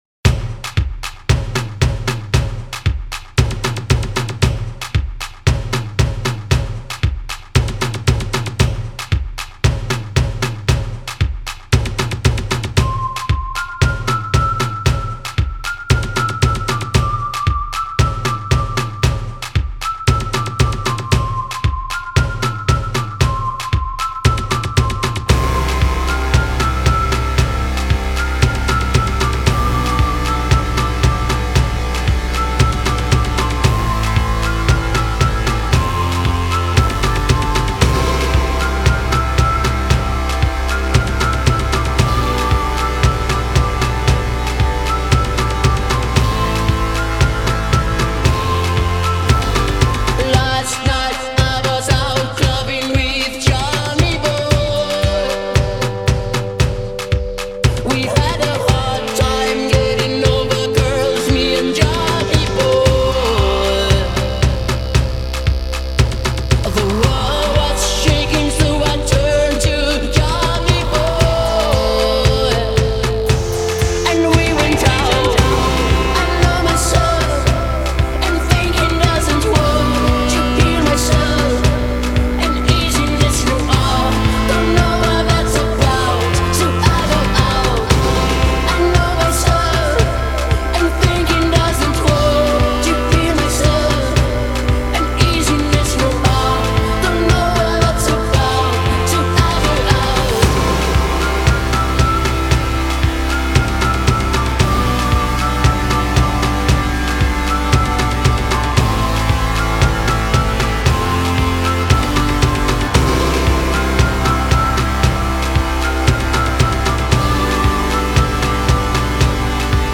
A drumbeat and a whistling melody